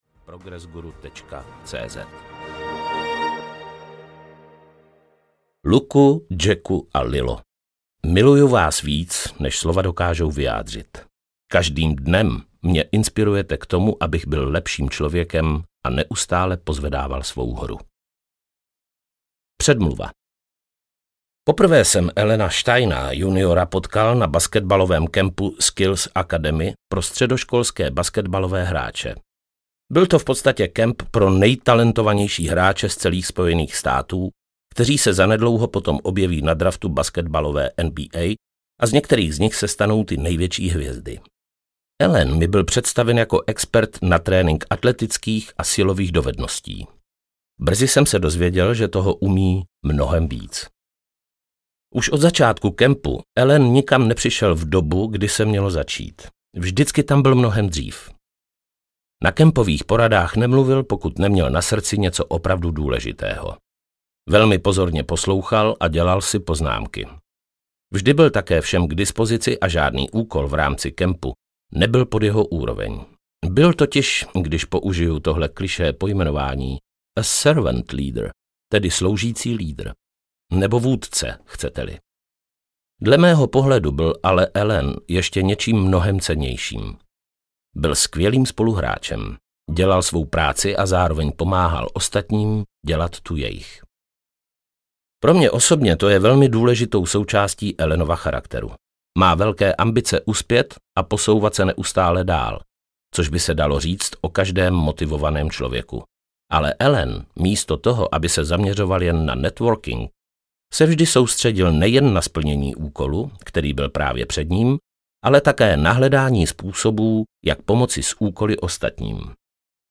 Pozvedněte svou hru audiokniha
Ukázka z knihy
• InterpretPavel Nečas